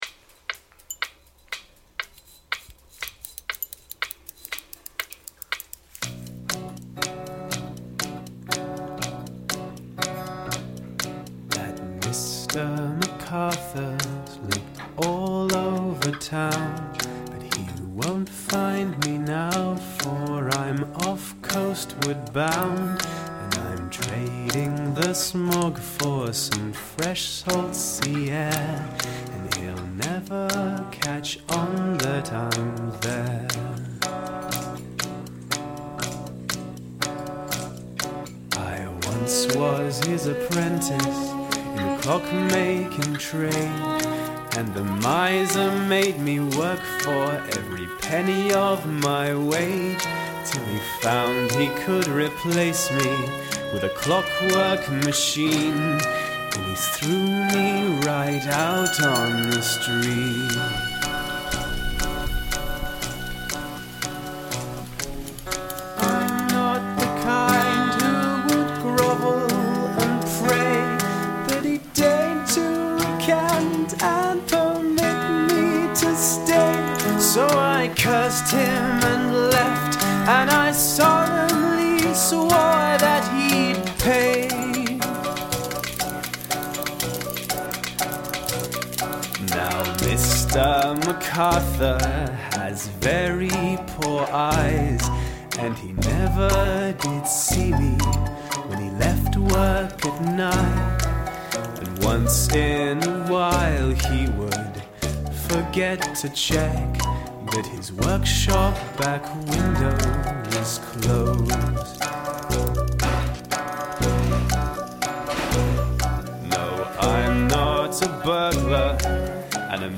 Жанр обощенно зовется стимпанк-мьюзик.